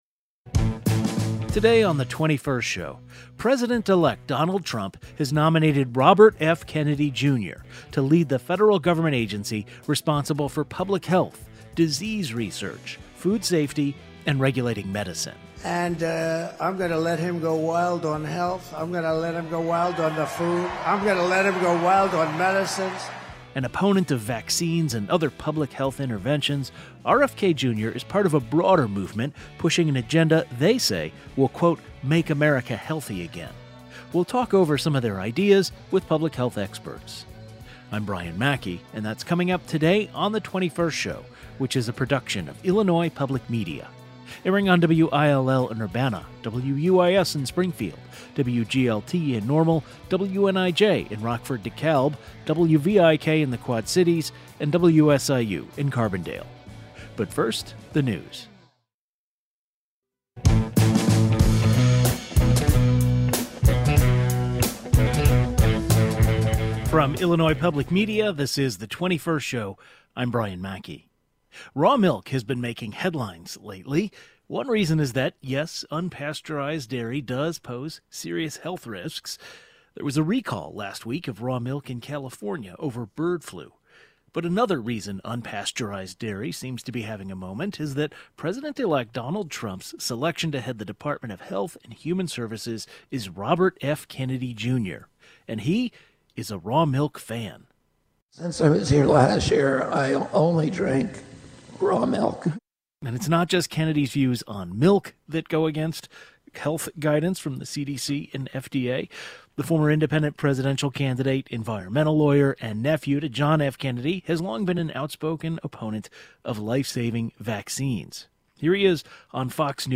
A panel of public health experts discuss some of the MAHA movement's ideas as well as what the Department of Health and Human Services could look like under RFK Jr.